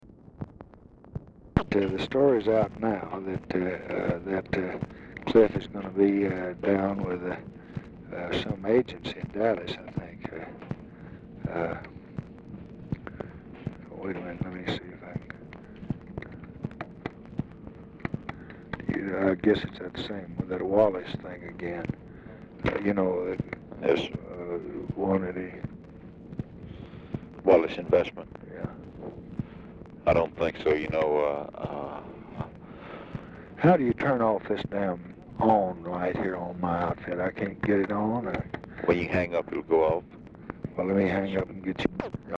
Telephone conversation # 9321, sound recording, LBJ and MARVIN WATSON?, 12/16/1965, 9:40AM?
RECORDING STARTS AFTER CONVERSATION HAS BEGUN AND ENDS BEFORE IT IS OVER
Format Dictation belt
Location Of Speaker 1 Mansion, White House, Washington, DC